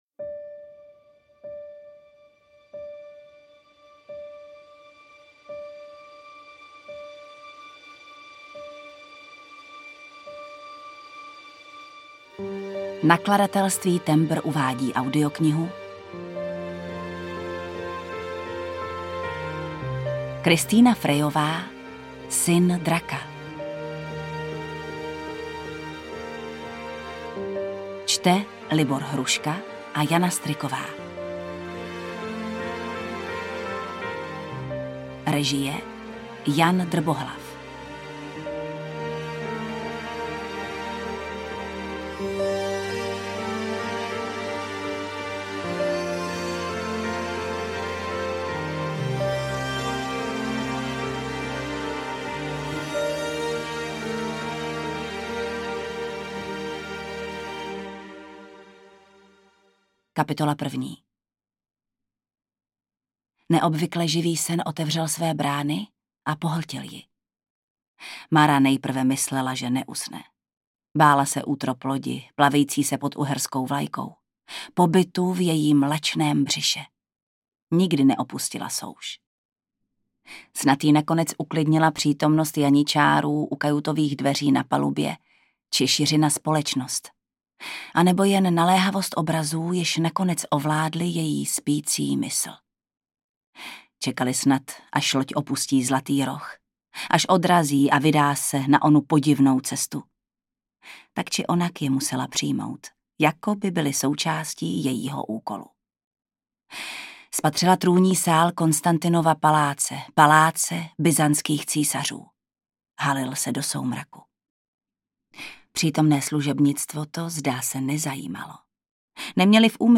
UKÁZKA Z KNIHY
audiokniha_syn_draka_ukazka.mp3